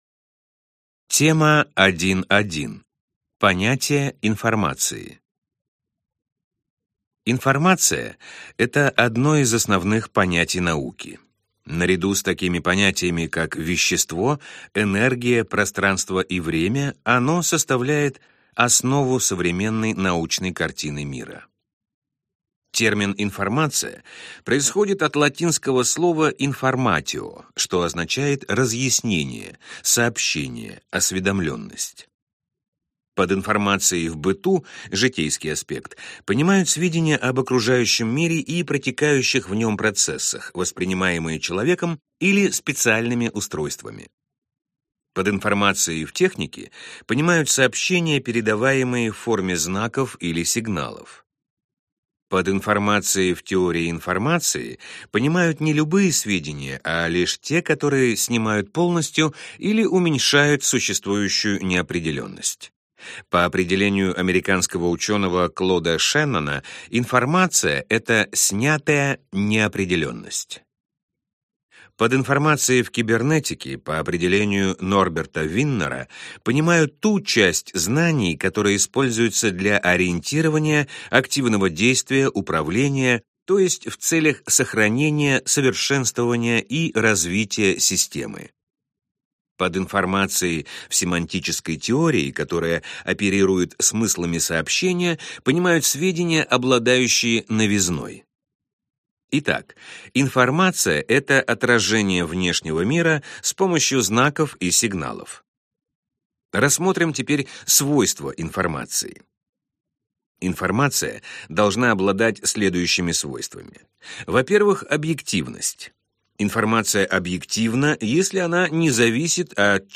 Аудиокнига Выпуск 12. Информатика. Книга для студентов | Библиотека аудиокниг